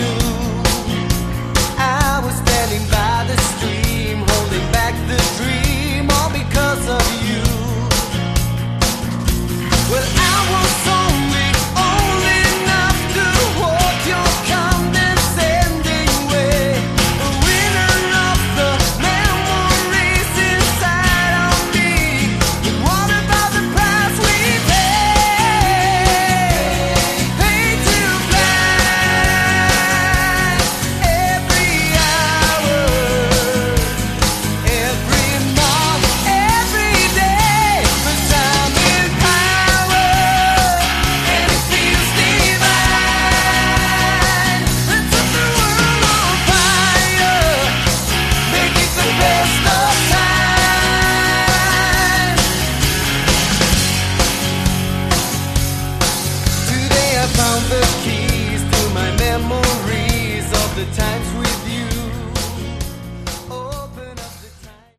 Category: AOR
lead and backing vocals
rhythm guitars, keyboards
bass, fretless bass, backing vocals
drums, percussion